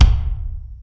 taiko-soft-hitfinish.ogg